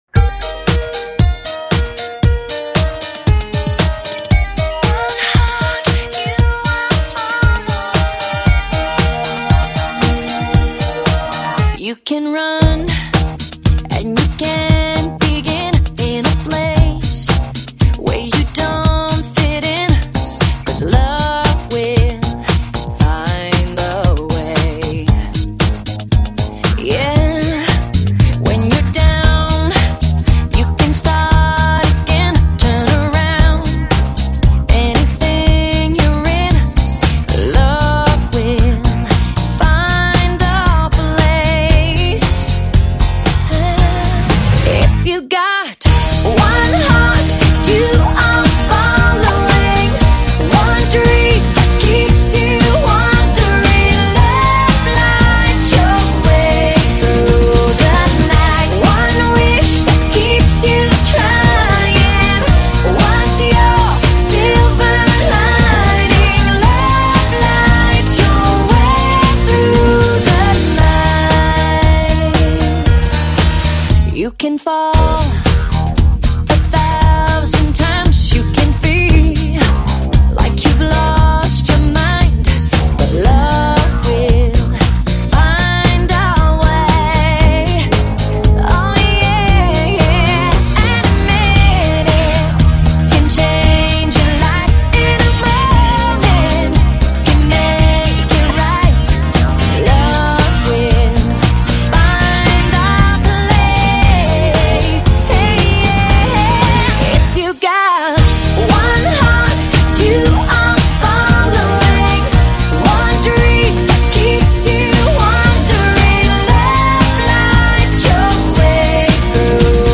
轻快愉悦的风格